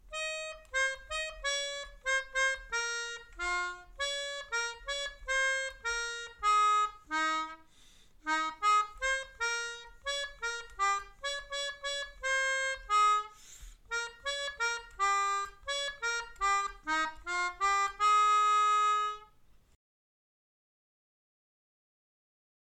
Melodica / Pianica / Airboard